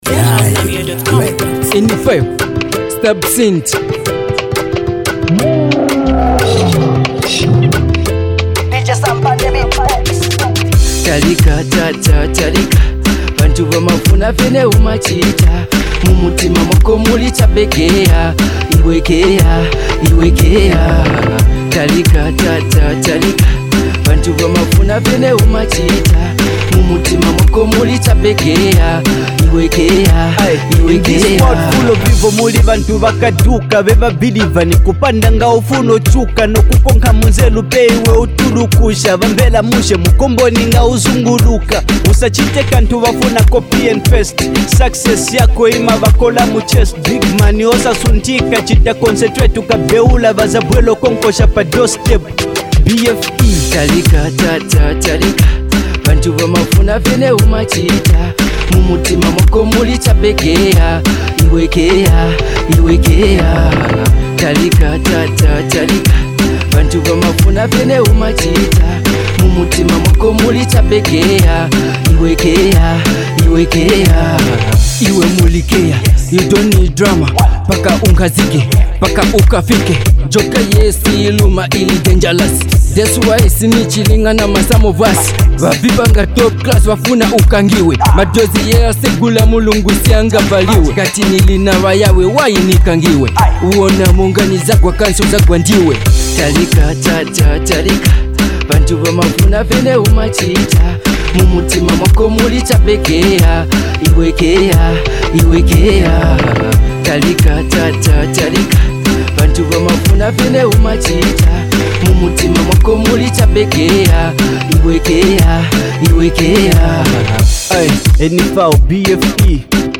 pure energy